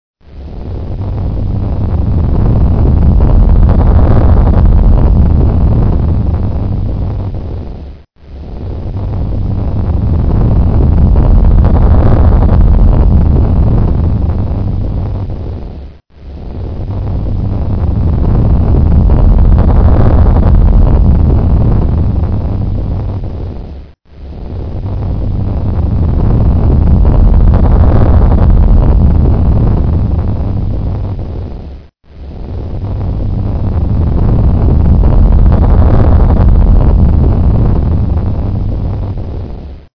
Rumbling Sounds ringtone free download
Sound Effects